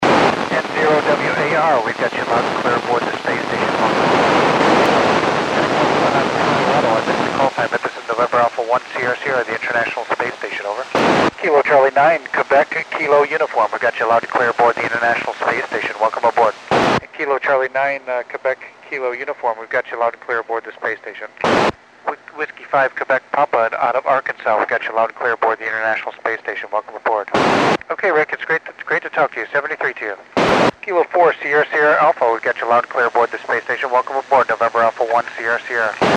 NA1SS (Col. Doug Wheelock) working U.S. stations on 22 November 2010 at 2124 UTC
This was an odd pass in that Col. Wheelock only transmitted during the first 3-4 minutes of the pass.